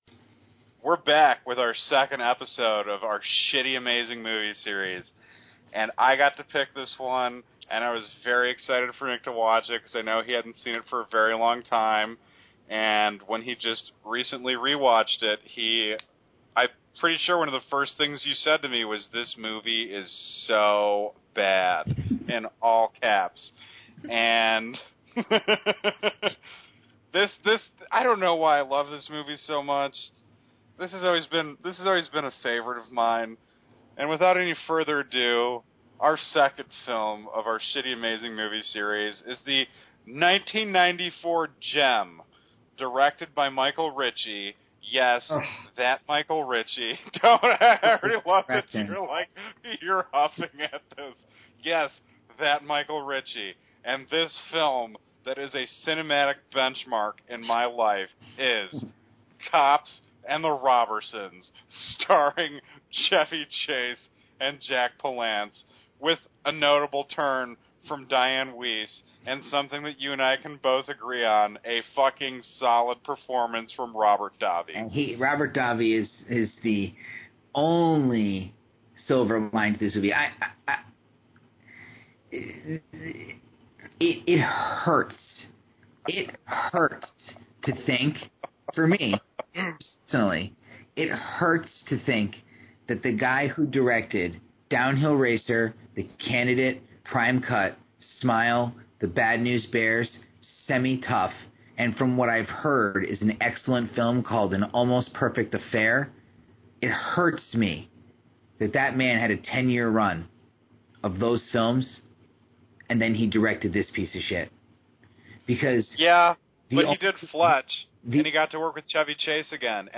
Move over, PULP FICTION, Michael Ritchie directed Chevy Chase, Jack Palance, Diane Wiess, and Robert Davi in this glorious National Lampoons knock-off, COPS AND THE ROBBERSONS. Enjoy this ten minute chat of giggling.